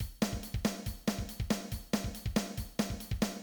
Drum beat
1 + 2 + 3 + 4 +                  1 + 2 + 3 + 4 +     S=snare K=kick  H=hihat  C=crash
D-beat_drum_pattern_2a.mid.mp3